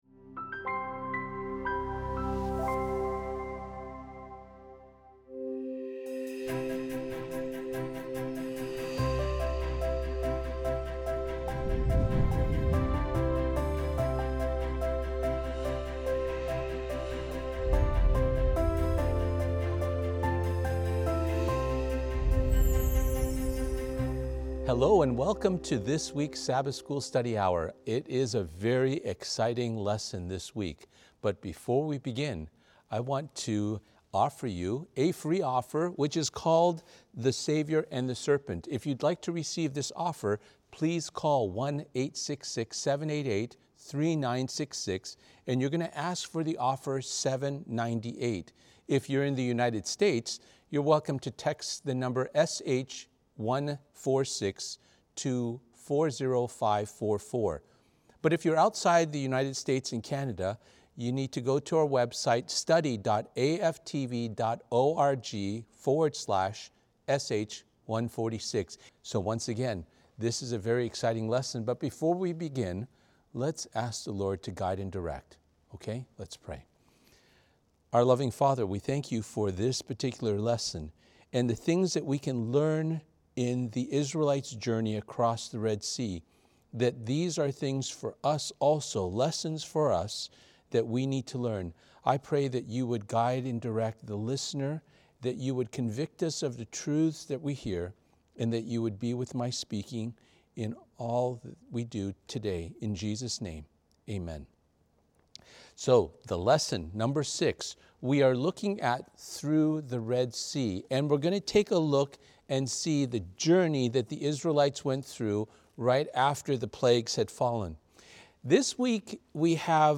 Latest was Unfolding Grace Sermon 14 (Israel’s Oppression and Moses’ Call Part 2; Exodus 2-3).